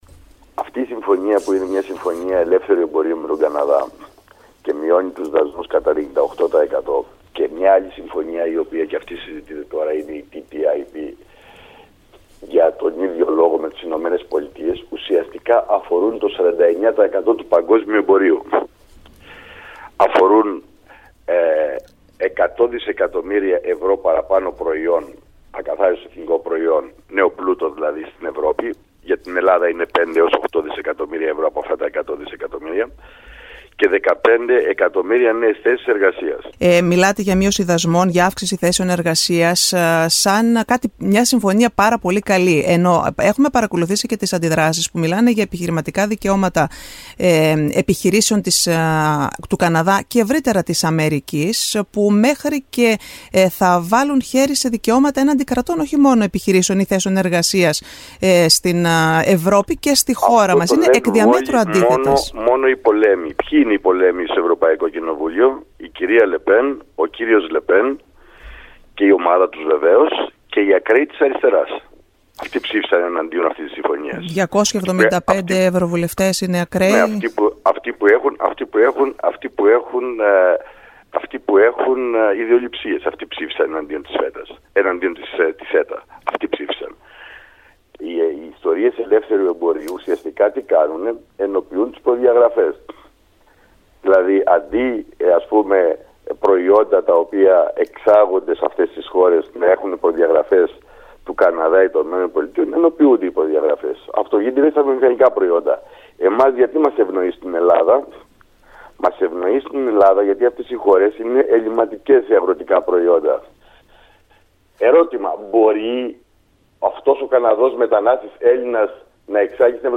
O ευρωβουλευτής Μανώλης Κεφαλογιάννης μιλά στην ΕΡα Αιγαίου για την συμφωνία της CETA